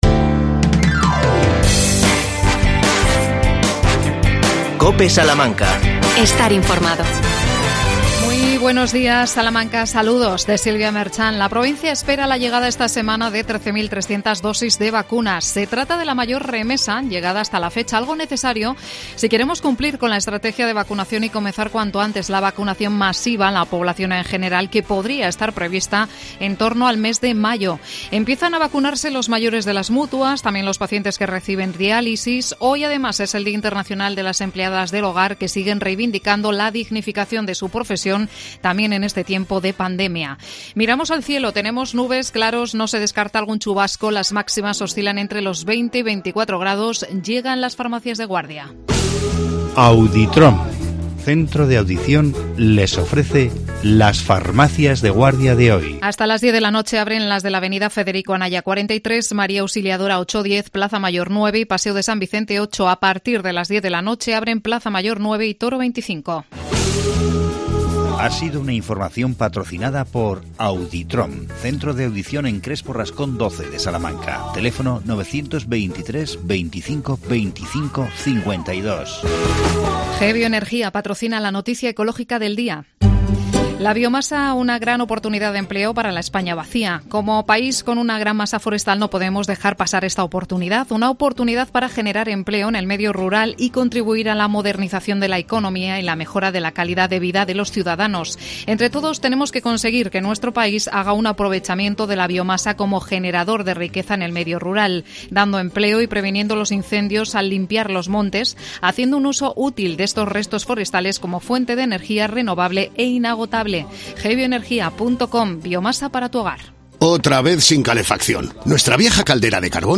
AUDIO: Ayuntamiento Informa.Hablamos de patrimonio con el concejal Daniel Llanos.